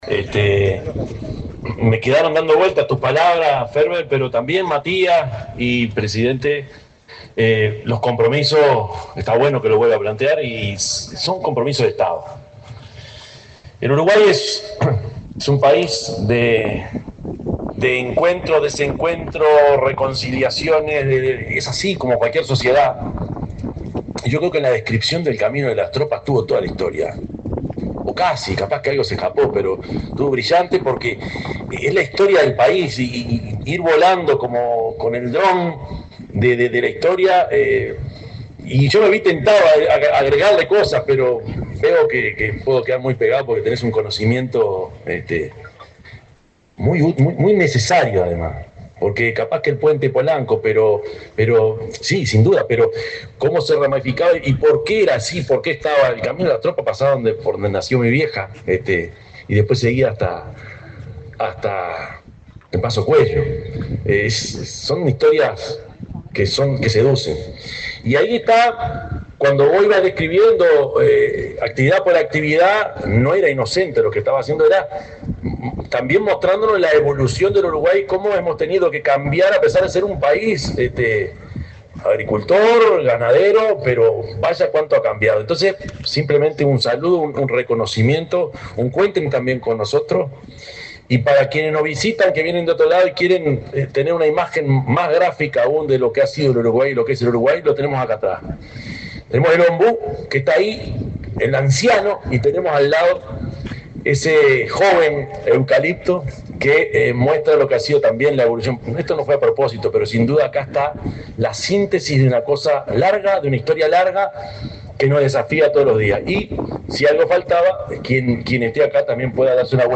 Palabras del presidente de la República, Yamandú Orsi
El presidente de la República, profesor Yamandú Orsi, encabezó, este jueves 3, la apertura de la exposición Rural de Melilla 2025, organizada por la